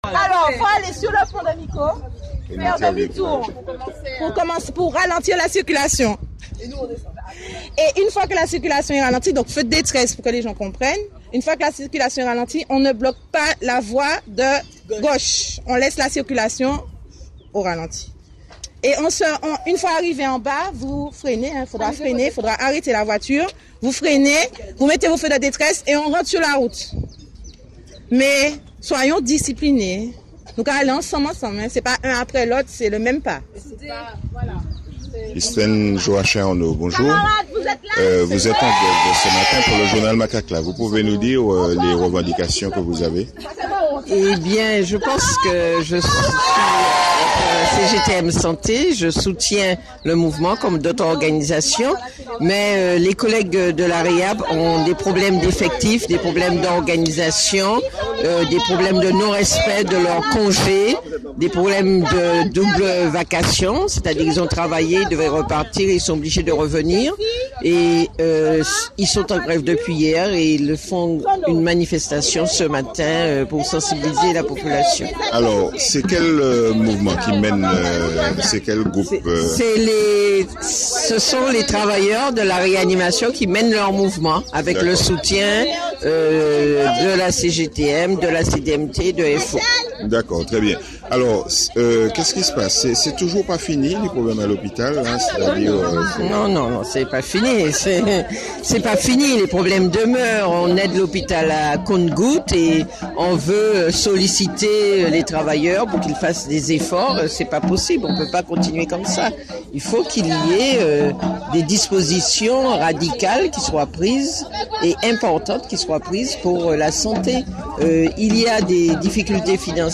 Écoutez ce reportage réalisé ce matin vendredi 7 avril 2017. C’était au moment de leur départ.